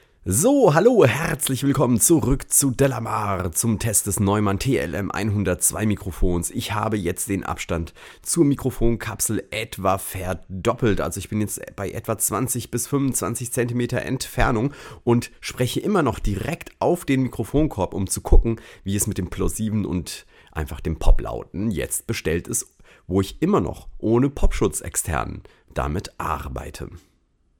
Generell klingt das Neumann TLM 102 relativ neutral und modern.
Neumann TLM 102 (hier) vs. U87ai